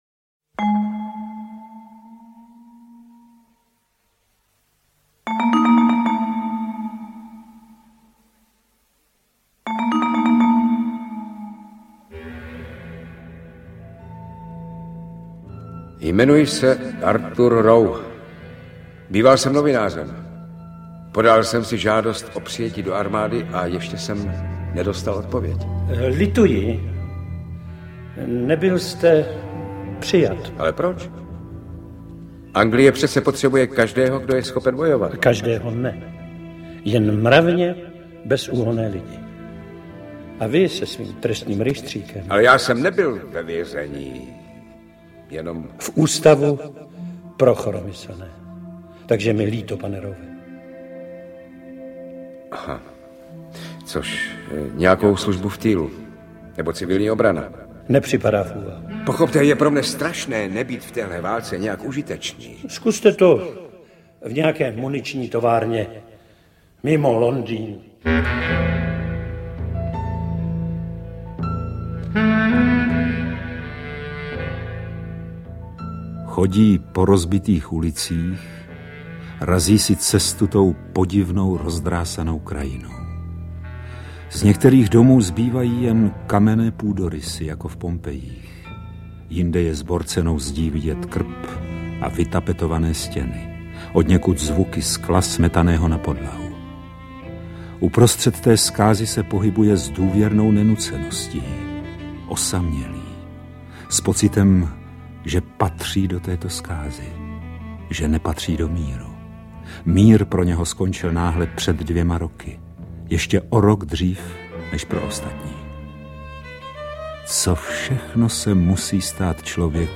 Navíc jsou tu skvělé herecké výkony.
AudioKniha ke stažení, 9 x mp3, délka 2 hod. 50 min., velikost 155,0 MB, česky